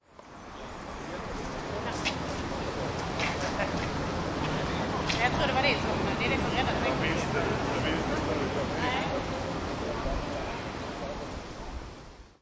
环境噪声
描述：可能是企业或酒店大厅的环境音景。是一个放松的环境。在Pompeu Fabra的大学，巴塞罗那以Zoom H2录制。
标签： 说话 大厅 工作问题 氛围 环境 背景 校园UPF 咖啡 聊天 町NE 放松 企业 个人 UPF-CS14 苦恼 噪音 大气
声道立体声